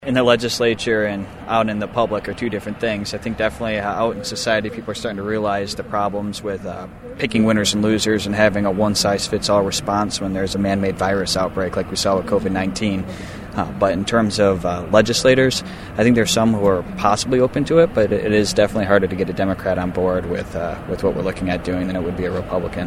PORTAGE, MI (WKZO AM/FM) — About two-dozen people gathered across Portage Road from Pfizer’s headquarters Sunday afternoon to call on the company to stop making COVID-19 vaccines. State Represenative Steve Carra of Three Rivers joined them and is calling on other lawmakers to join him in putting pressure on Pfizer.